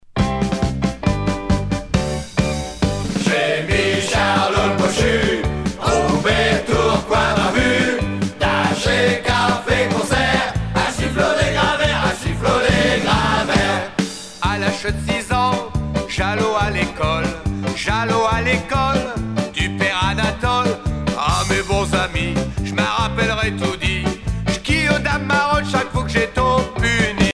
danse : marche
Genre strophique
Pièce musicale éditée